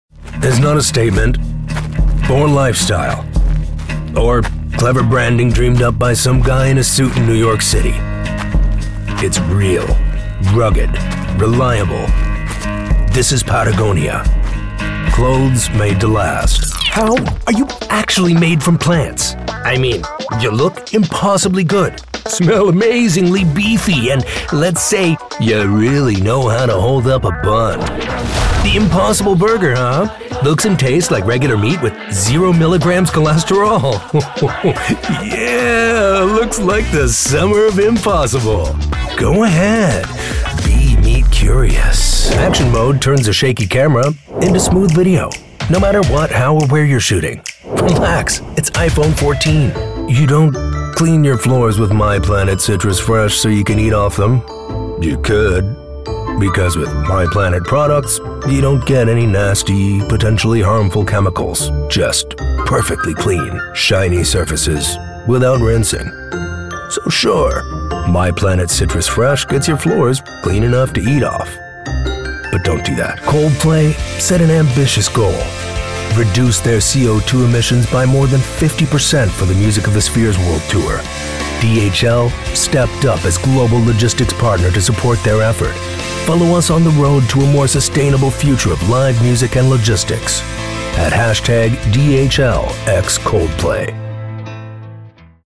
English (American)
Deep, Natural, Distinctive, Versatile, Warm
Commercial